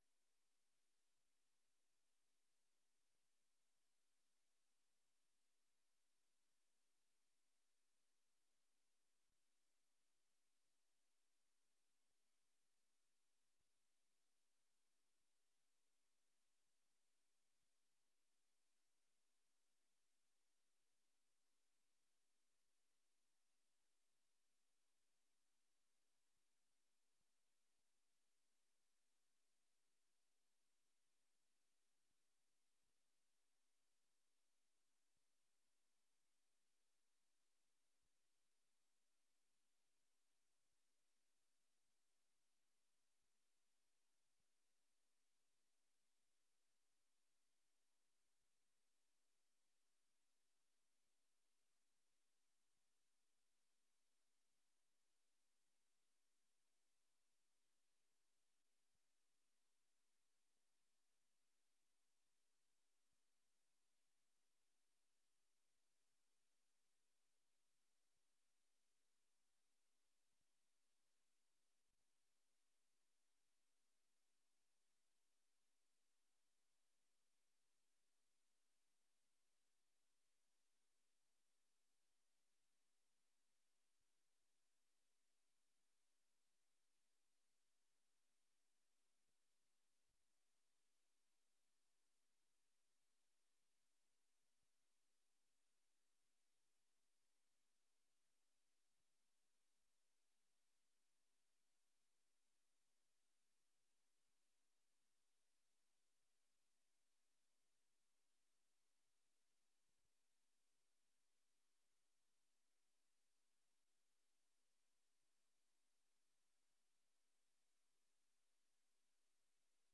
Raadsvergadering 28 maart 2024 19:30:00, Gemeente Dronten
Locatie: Raadzaal